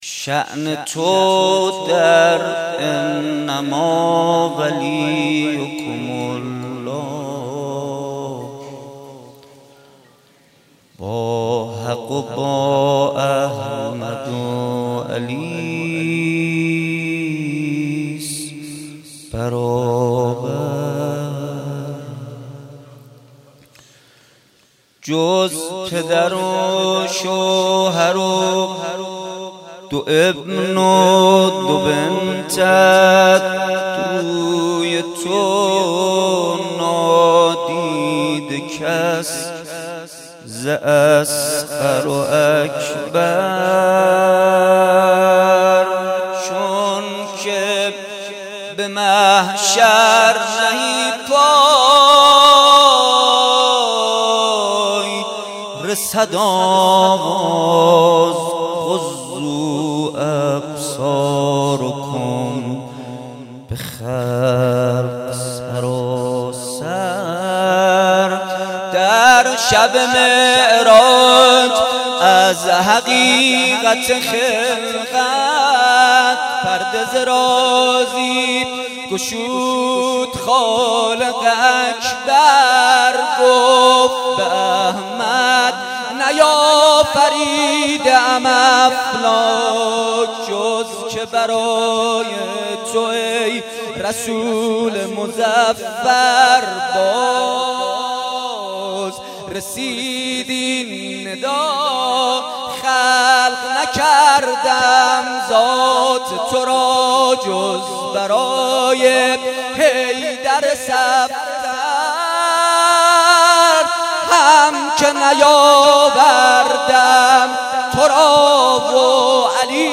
ولادت حضرت فاطمه (س)